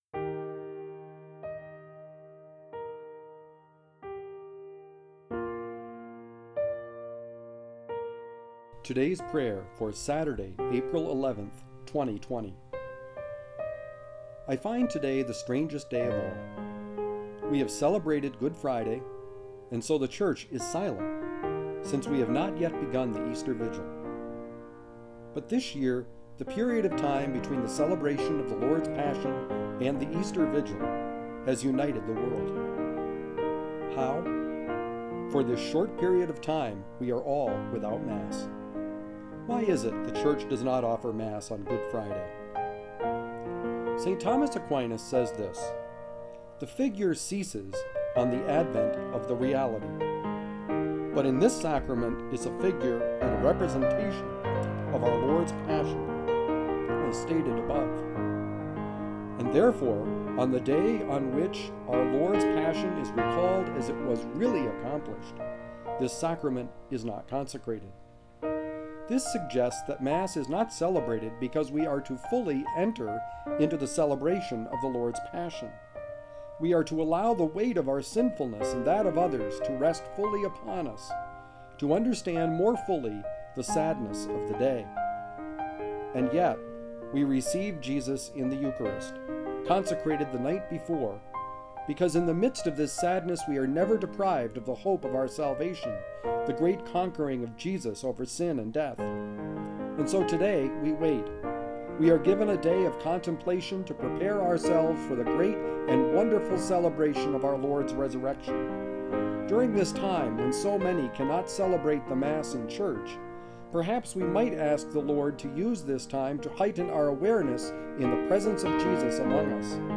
Music: Cheezy Piano Medley by Alexander Nakarada